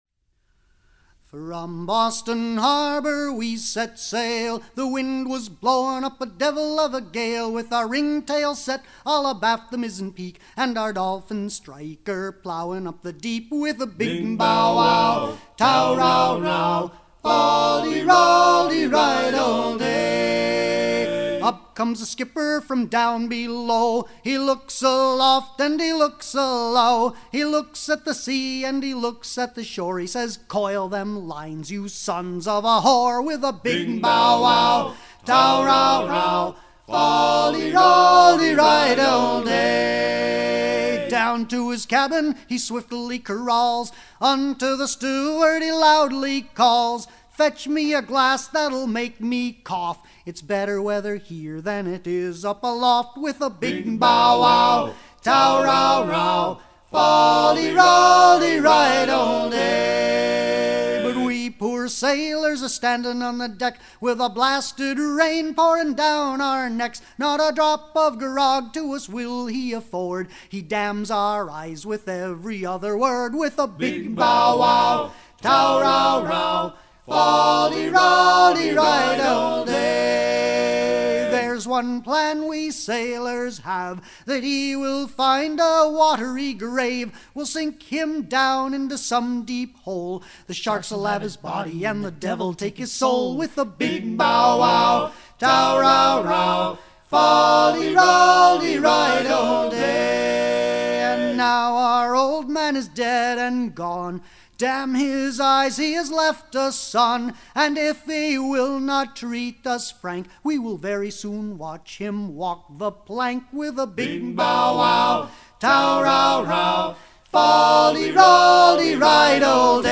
à virer au cabestan
circonstance : maritimes
Genre strophique
Pièce musicale éditée